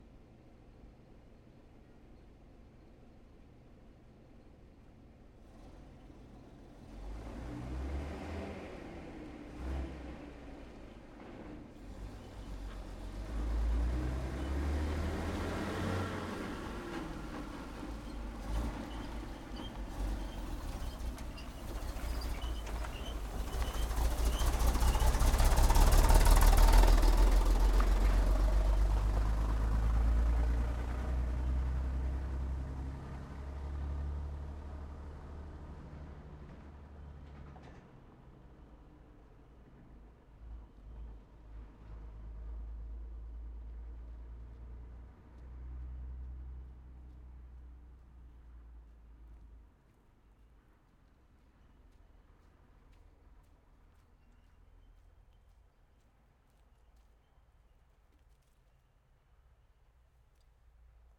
Pole Position - M1 Corbitt Prime Mover Cargo Truck